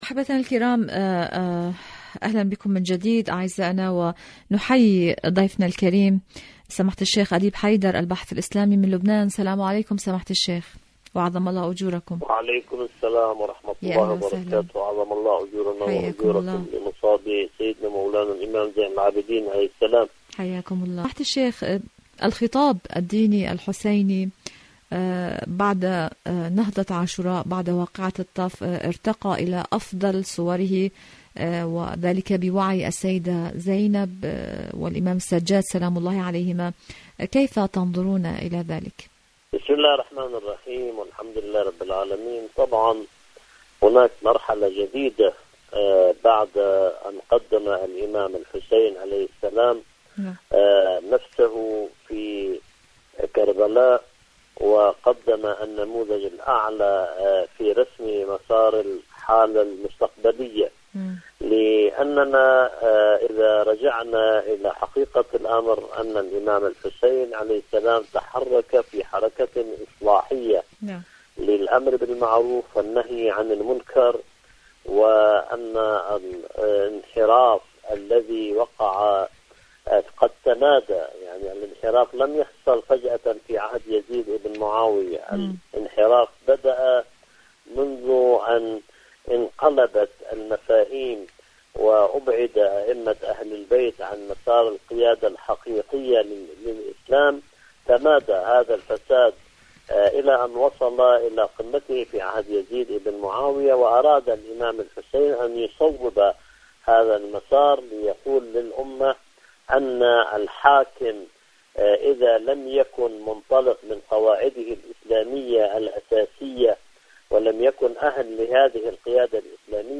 مقابلات برامج إذاعة طهران برنامج دنيا الشباب الشباب الخطاب الديني الشباب والخطاب الديني مقابلات إذاعية الخطاب الدين الخطاب الديني الحسيني شاركوا هذا الخبر مع أصدقائكم ذات صلة الردع الإيراني والمقاومة الفلسطينية..